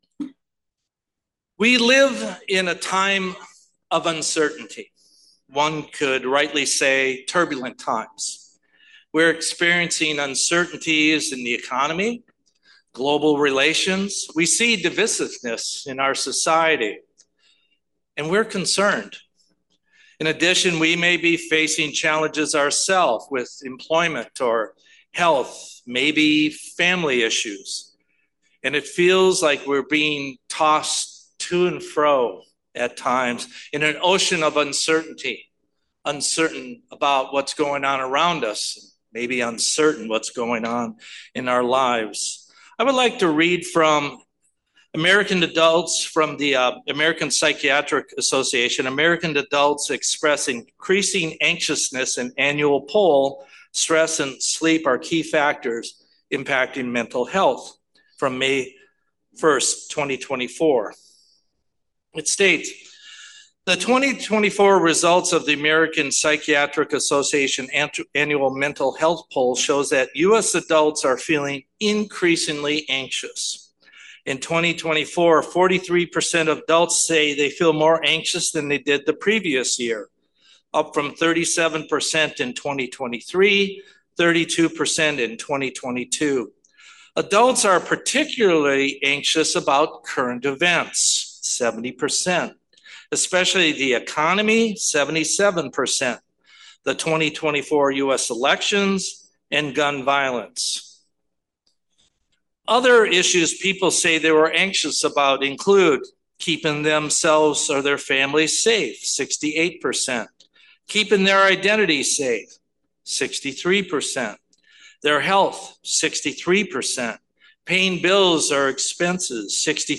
Given in Los Angeles, CA Bakersfield, CA